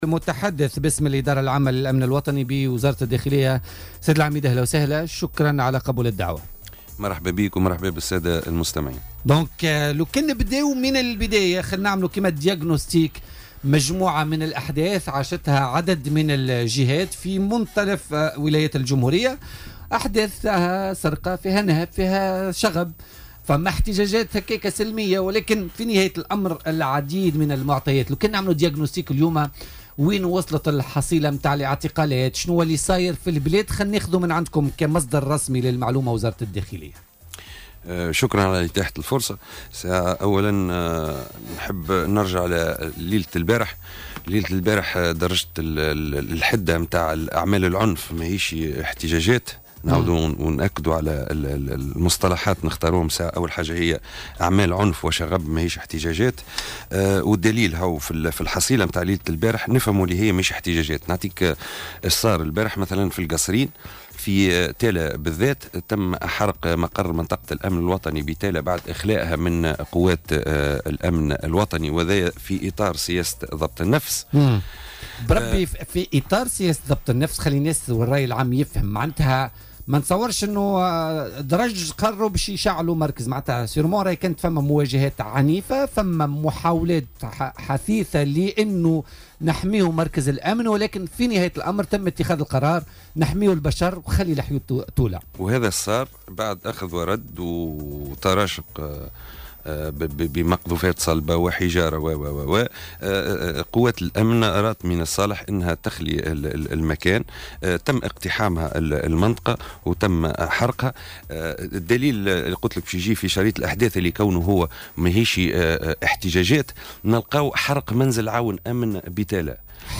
وقال ضيف "بوليتيكا" على "الجوهرة اف أم" إنه تم أمس ايقاف 328 عنصرا تورطوا في جرائم الشغب والحرق والنهب وقطع الطرقات واعتداء على ممتلكات العامة والخاصة وفي اكبر حصيلة منذ اندلاع احداث الشغب منذ الاثنين.